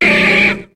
Cri de Machopeur dans Pokémon HOME.